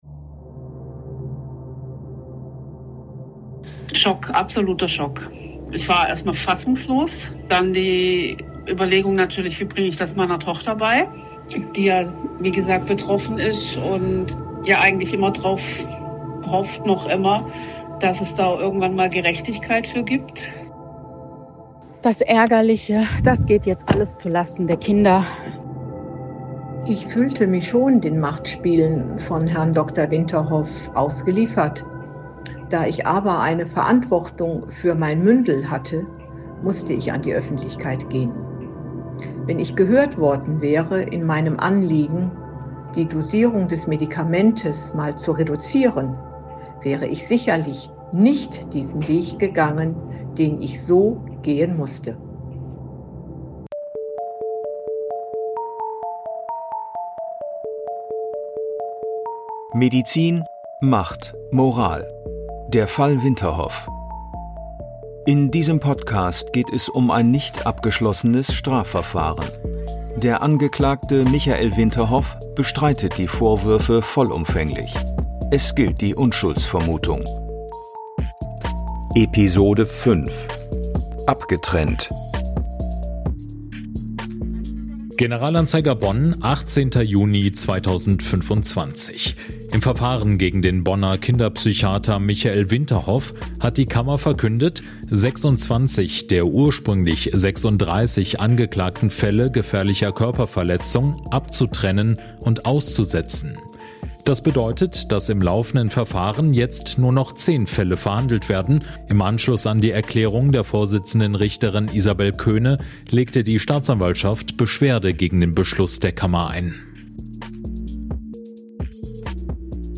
Was bedeutet die Entscheidung der Strafkammer juristisch – und wie erleben die betroffenen Kinder, Jugendlichen und ihre Familien diesen Einschnitt? Mit Stimmen aus dem Gerichtssaal, Einblicken in die Jugendhilfe, und einem kritischen Blick auf ein Verfahren, das für manche zur Geduldsprobe wird.